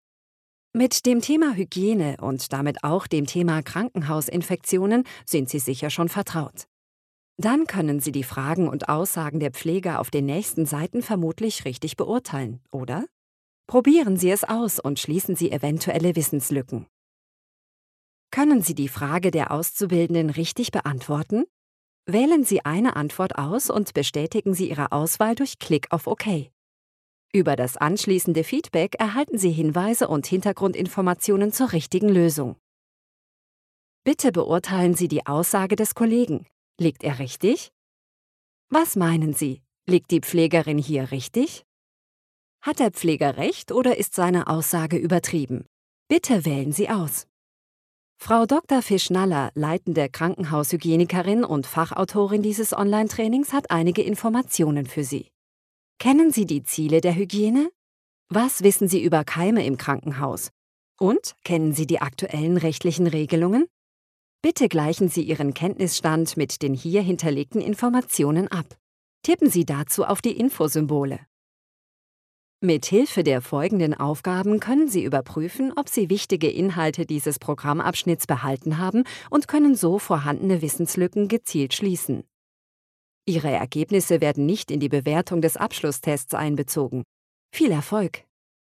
Ausgebildete Sprecherin mit eigenem Studio!
Sprechprobe: eLearning (Muttersprache):